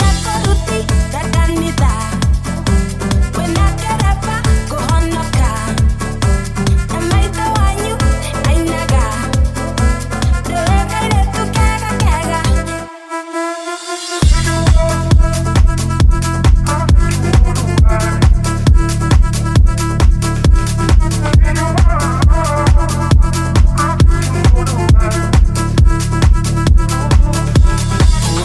Genere: pop,dance,afrobeat,house.remixhit